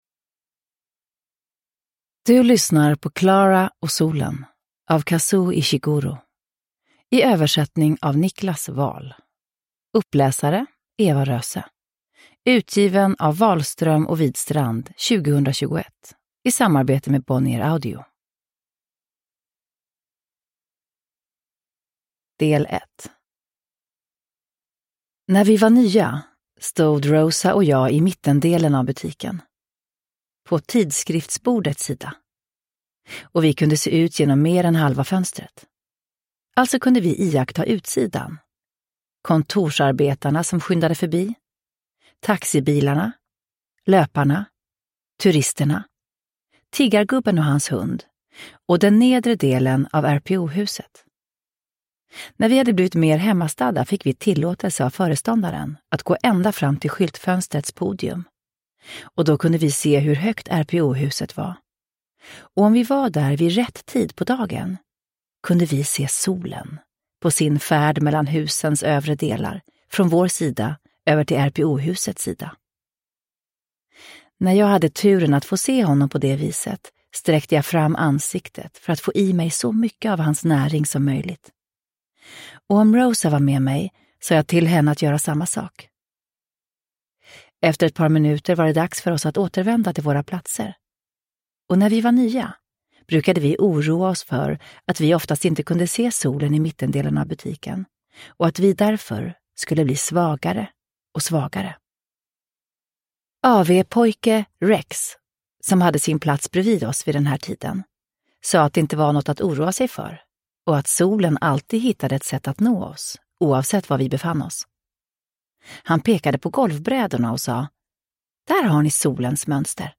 Klara och solen – Ljudbok – Laddas ner
Uppläsare: Eva Röse